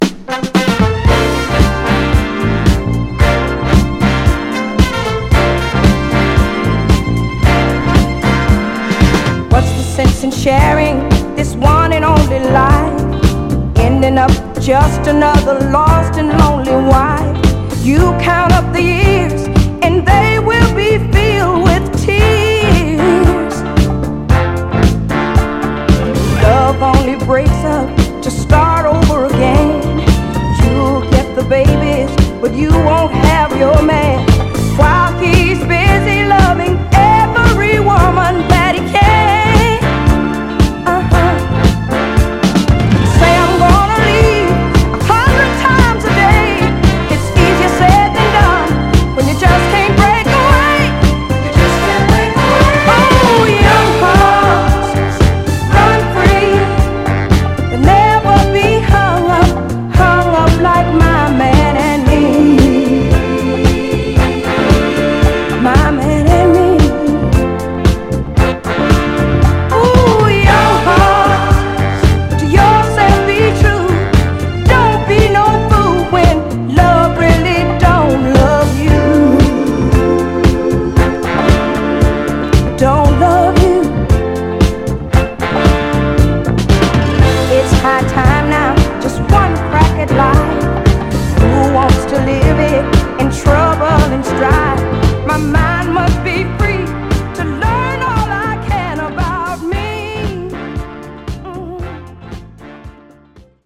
爆発力のあるホーン・セクションと高揚感あふれるヴォーカルで魅せる、溌剌ソウル・ダンサーです！
※試聴音源は実際にお送りする商品から録音したものです※